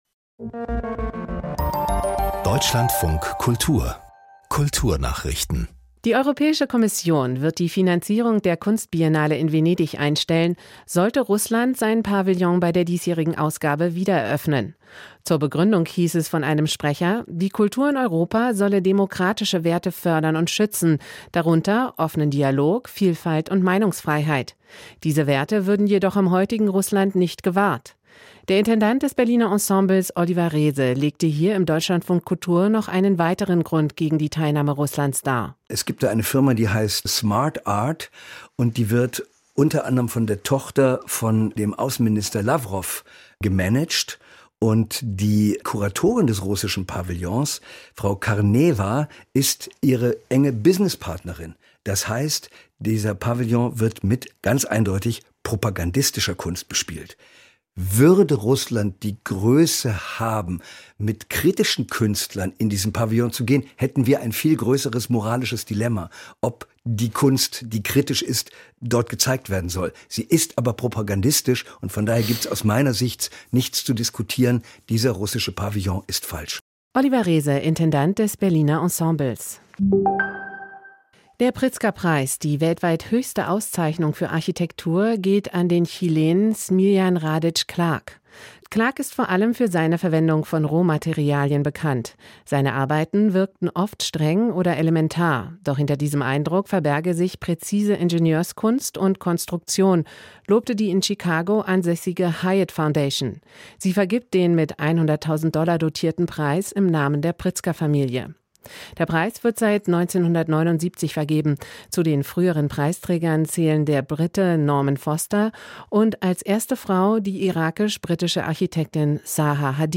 Kulturnachrichten